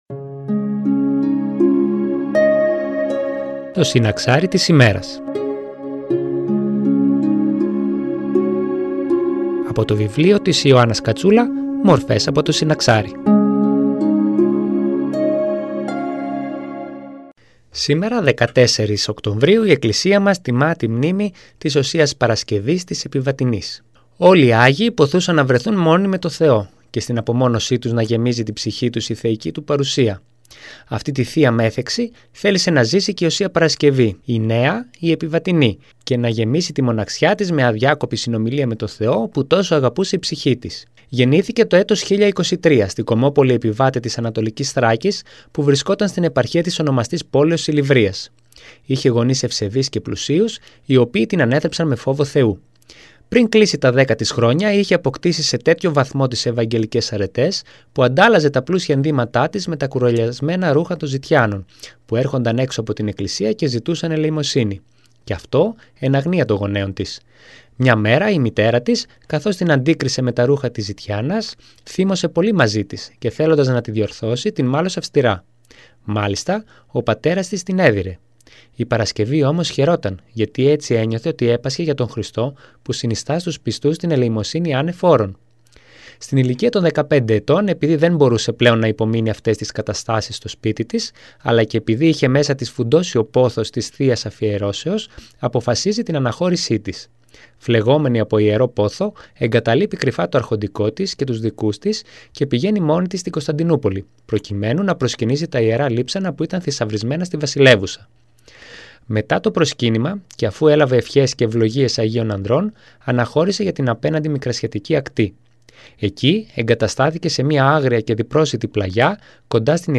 Η παρούσα ομιλία έχει θεματολογία «14 Οκτωβρίου – Οσία Παρασκευή Επιβατινή».
Εκκλησιαστική εκπομπή